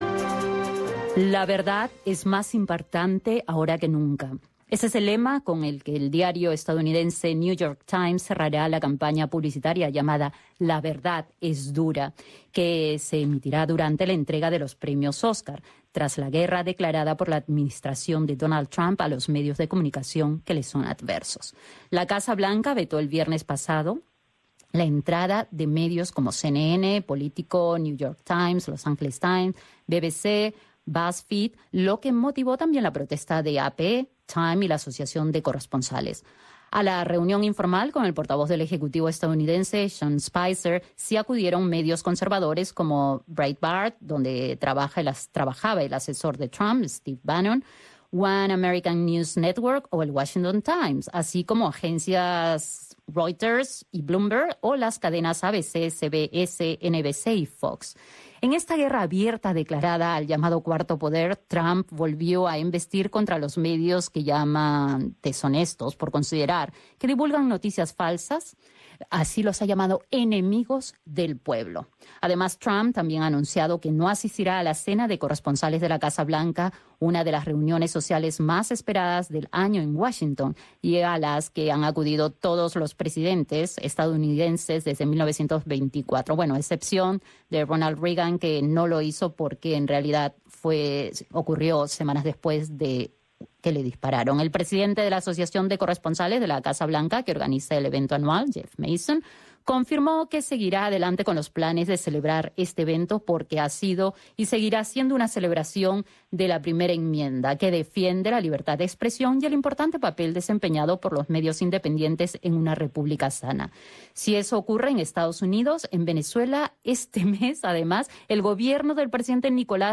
La Casa Blanca está enfrentada a algunos medios, entre ellos CNN, cuya transmisión ha sido paradójicamente suspendida a principios de mes por el Gobierno del presidente venezolano Nicolás Maduro. Conversamos con el analista